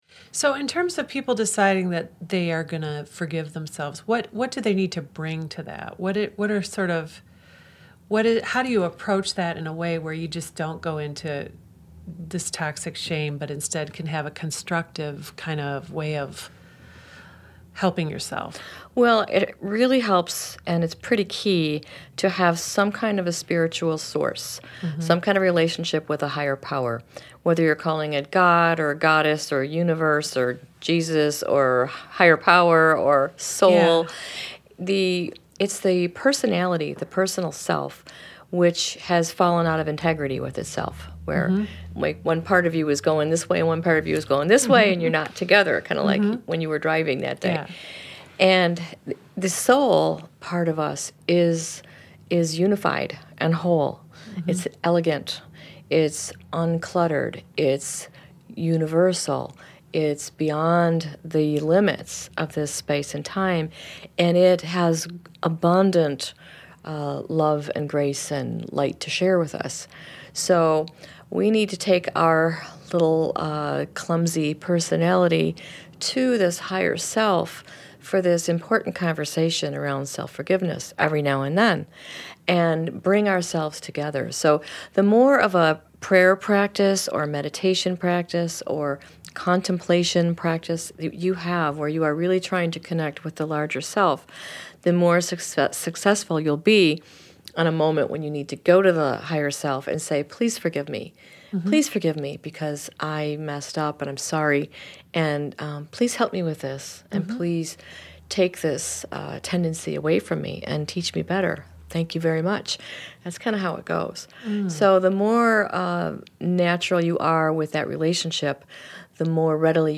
discuss: